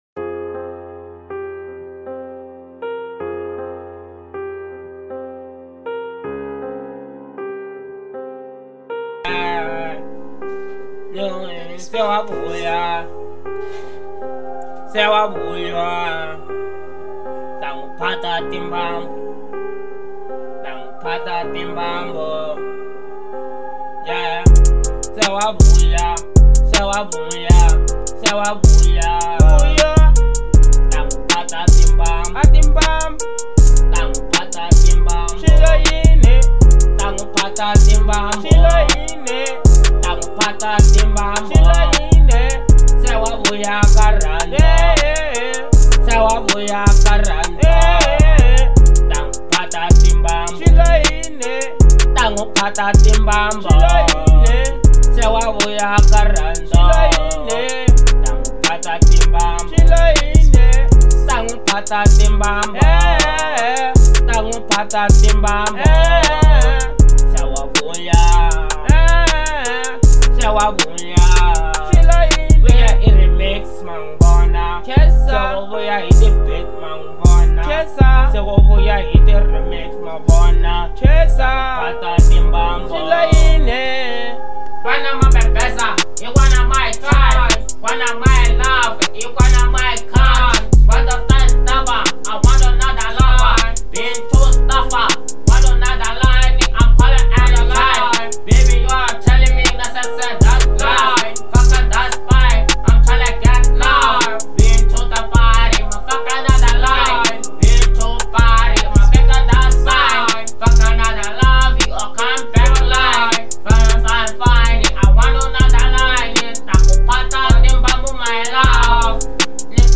02:26 Genre : African Kwasa Size